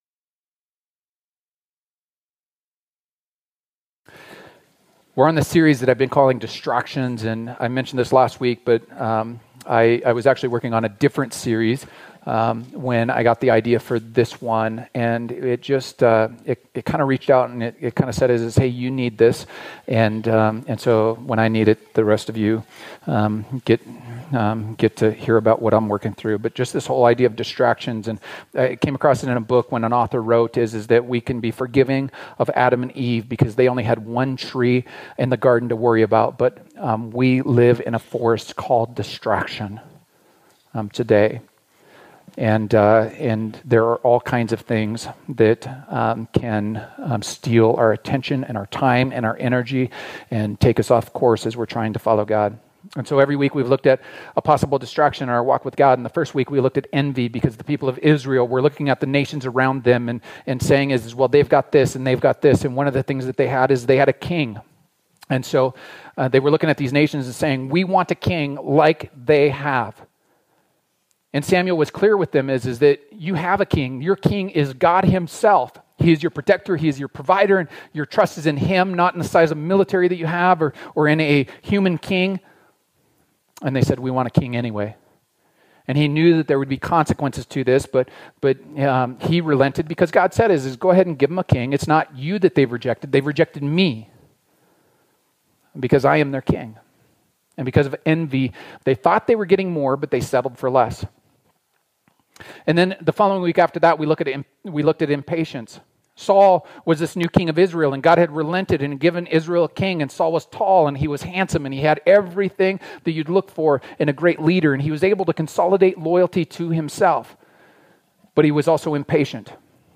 Our current sermon series is called Distractions. Life is full of Distractions that can throw us off course.